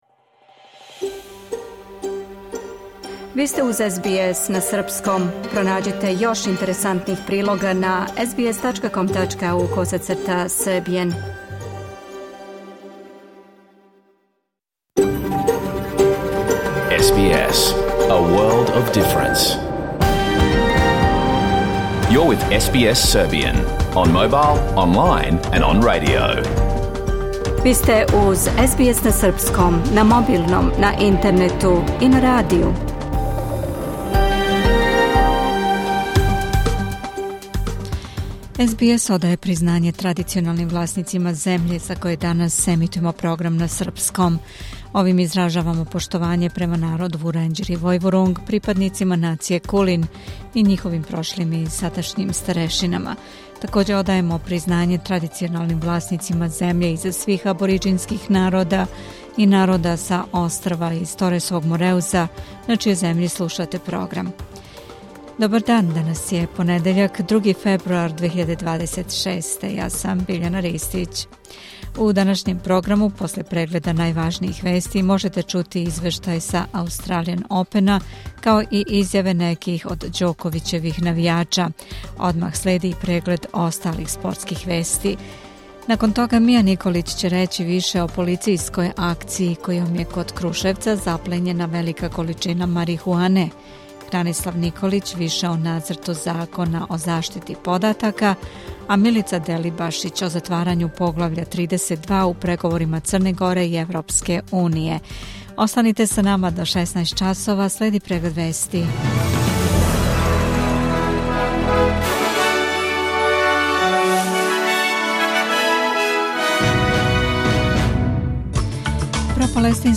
Програм емитован уживо 2. фебруара 2026. године